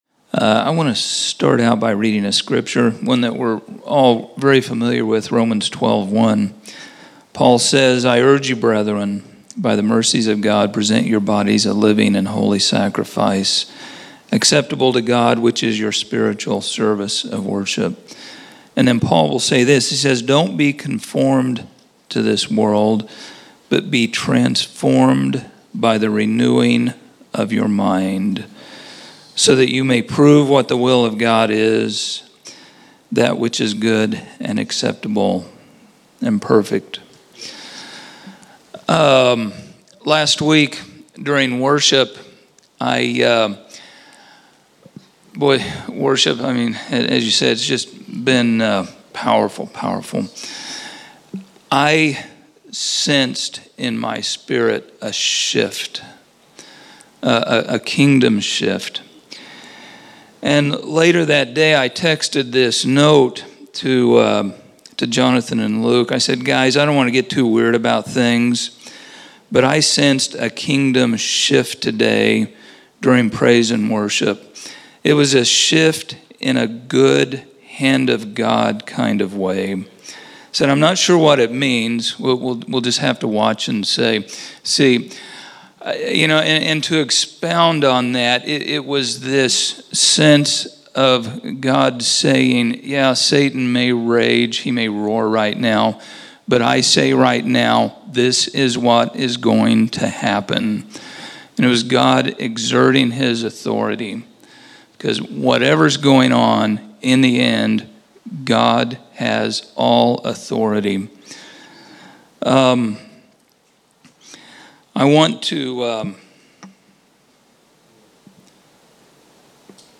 Category: Exhortation      |      Location: El Dorado